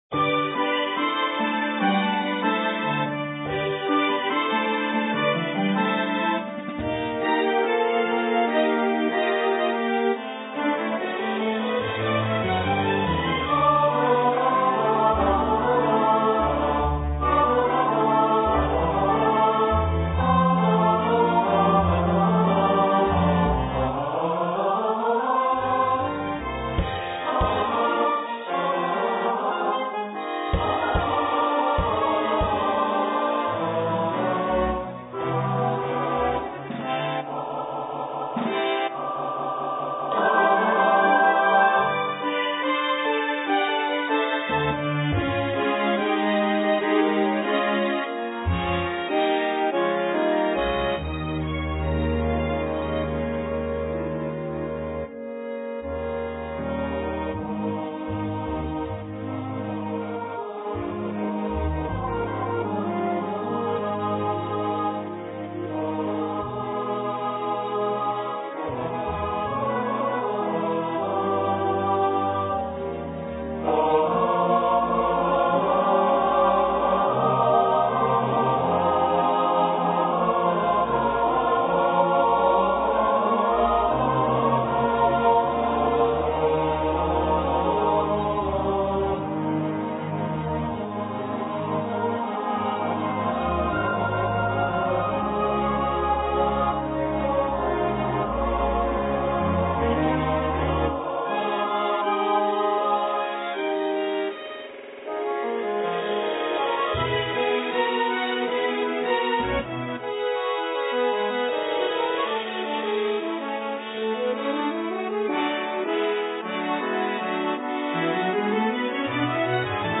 for mixed voice choir and orchestra
Flute*, Oboe*, Clarinet in Bb*, Bassoon*, 2 Horns in F,
Strings (Violin 1, Violin 2, Viola, Violoncello, Bass)